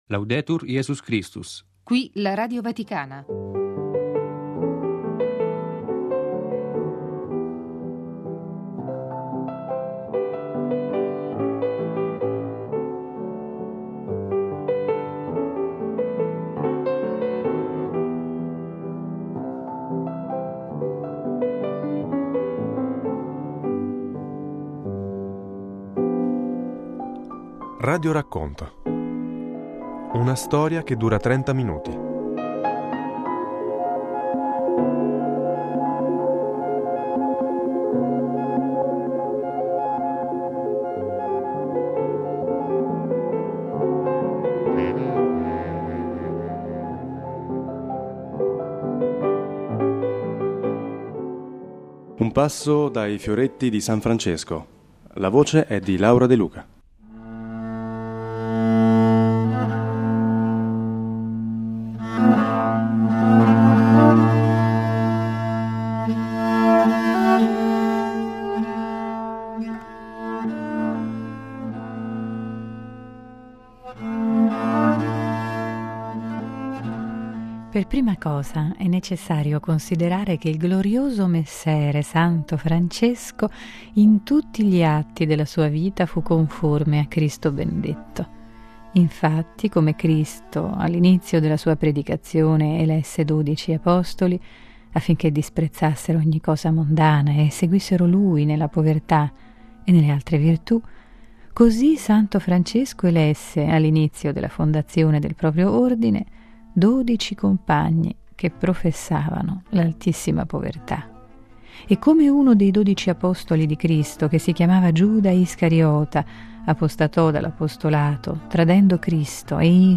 RADIORACCONTO: I Fioretti di San Francesco